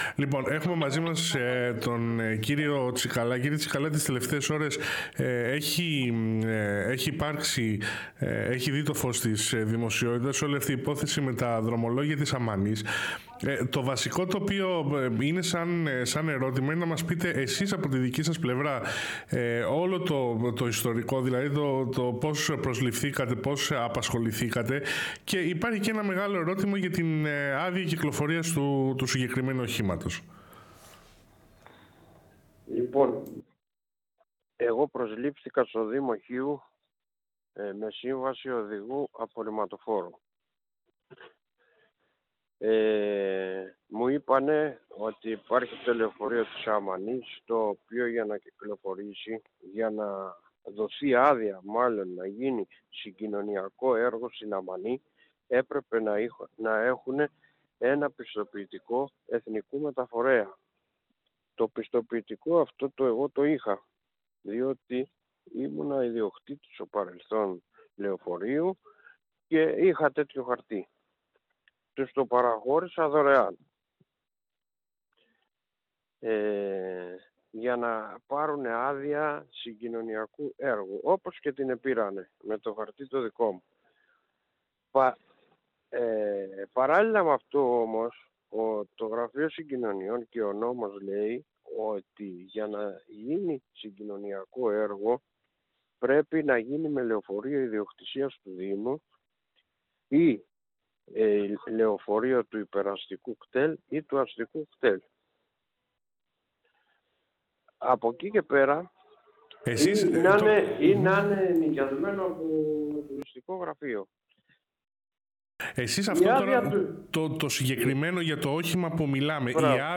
Τηλεφωνική Συνέντευξη